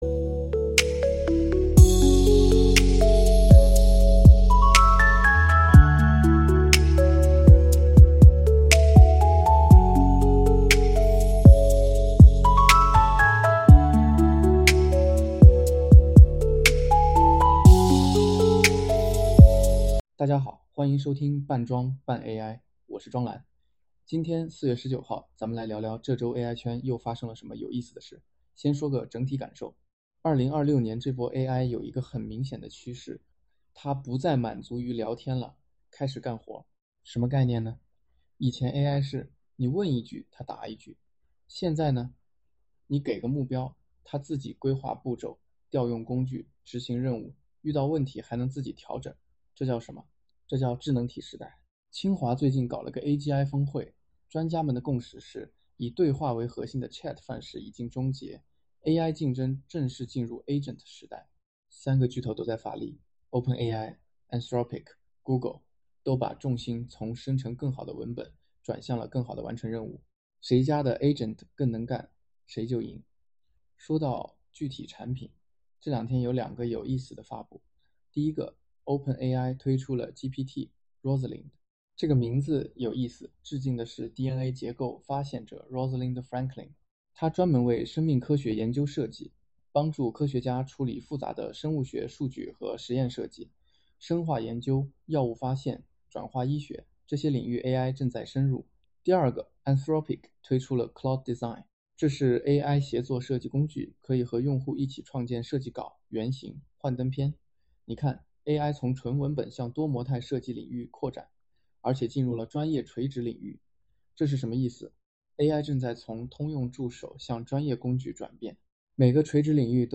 聊聊最近的人形机器人半马夺冠、Claude Opus 4.7系统提示词变化。包含开头结尾音乐，完整版8分33秒。